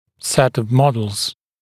[set əv ‘mɔdlz][сэт ов ‘модлз]набор моделей, комплект моделей